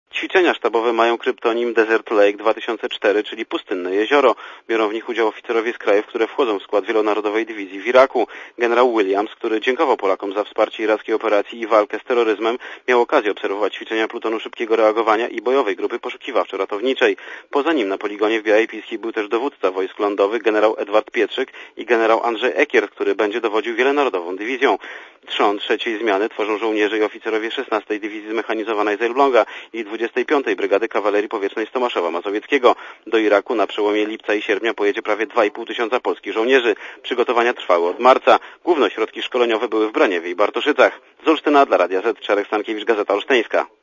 reportera Radia ZET*Komentarz audio